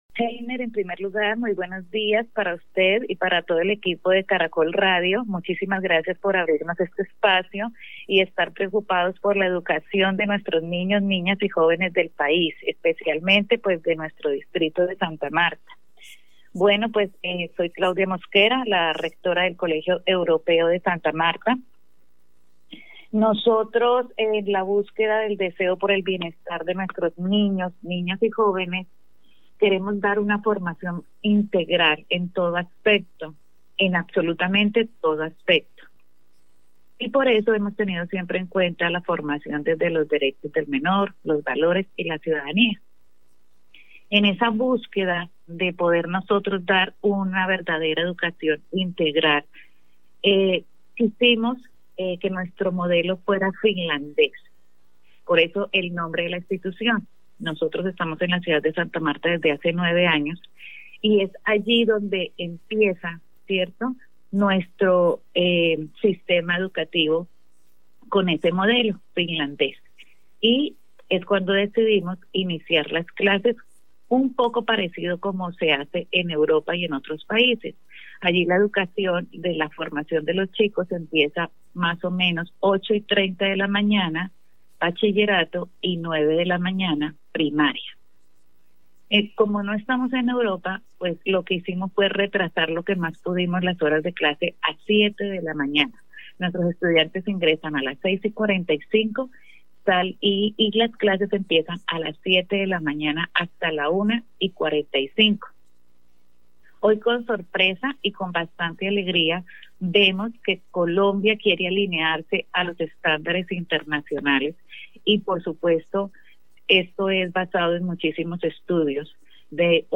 En diálogo con Caracol Radio, las directivas del European School de Santa Marta,explicaron los beneficios que tiene el que los estudiantes y docentes no madruguen para ir a la escuela.